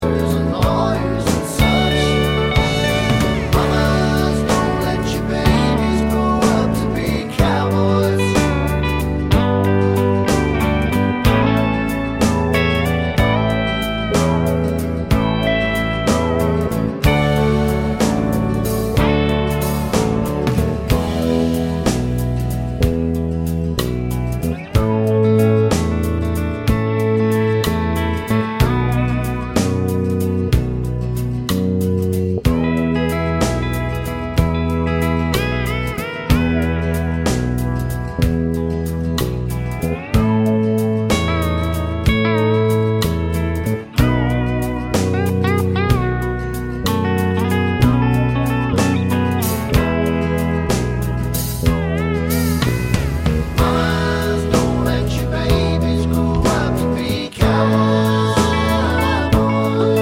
Duet Version Country (Male) 2:55 Buy £1.50